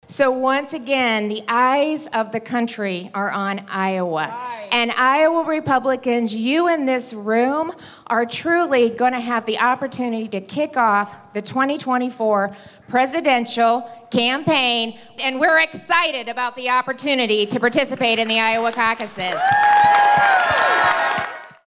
Haley told the crowd she was proud to serve in Trump’s Administration as United Nations Ambassador. After someone in the crowd asked about the war in Ukraine, Haley expressed admiration for the people of Ukraine and support for sending U.S. military equipment and ammunition to the fight.